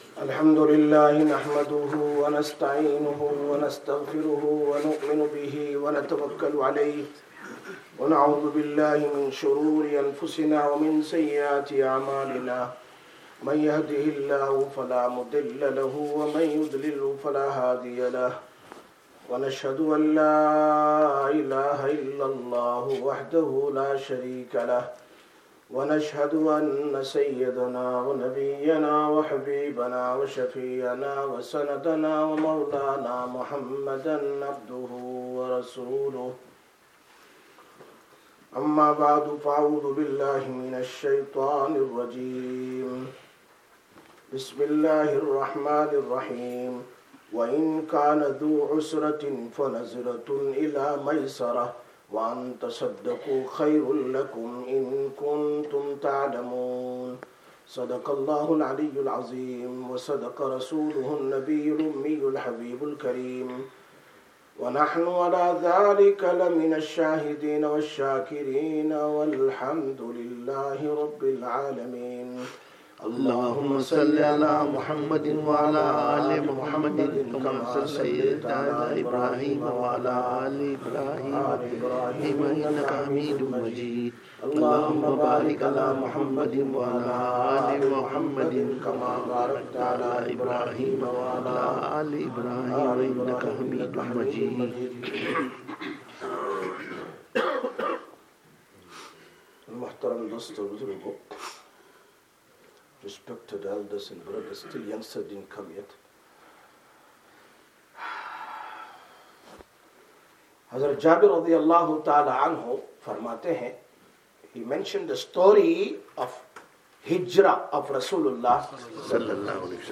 03/04/2026 Jumma Bayan, Masjid Quba